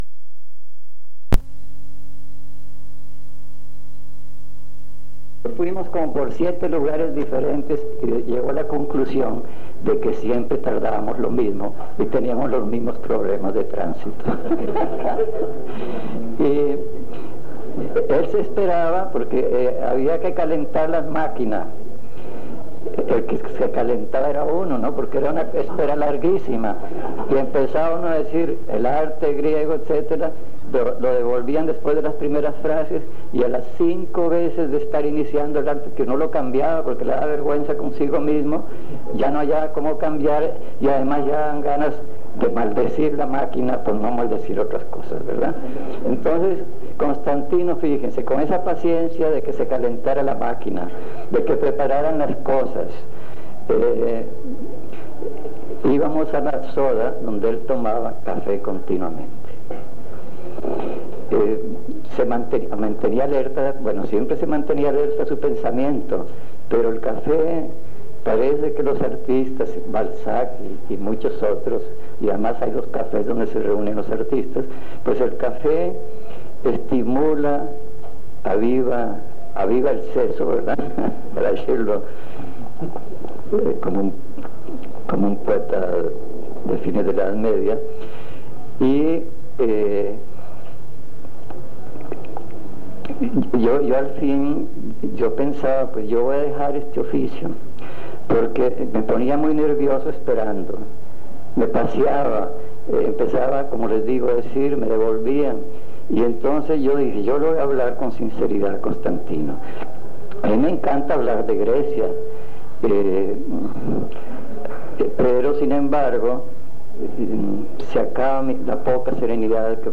Mesa Redonda “Constantino Lascaris”
V Congreso Centroaméricano de Filosofía.